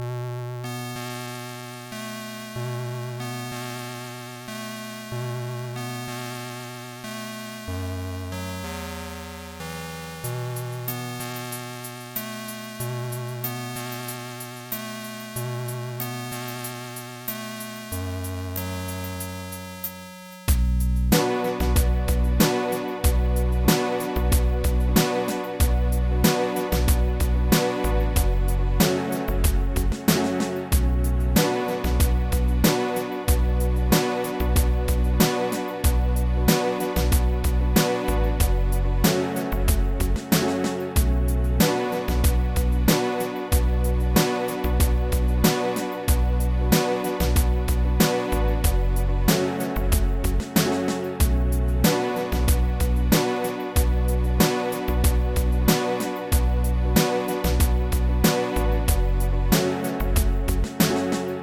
Small mod suitable for a loading-screen.
• 4 ch, 8 bit, 31388 Hz PAL / 31677 Hz NTSC
• MOD/Chip/SPC: Sounds best in stereo
• Music requires/does smooth looping